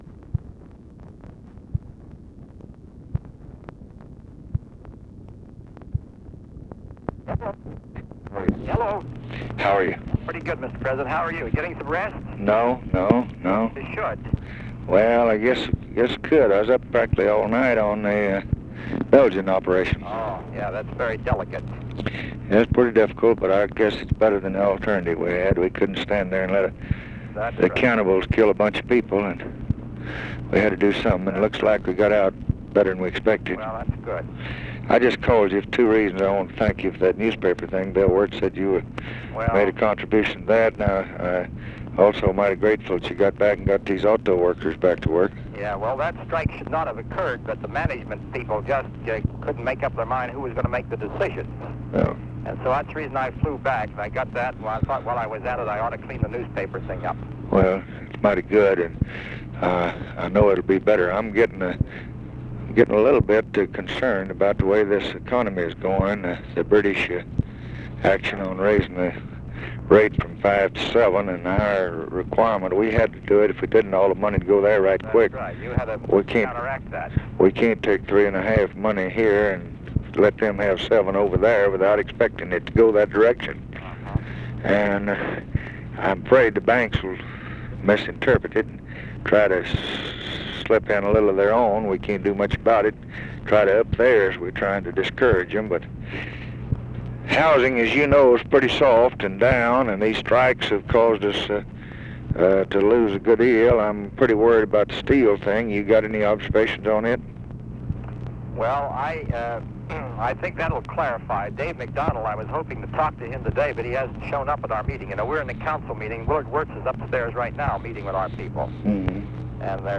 Conversation with WALTER REUTHER, November 24, 1964
Secret White House Tapes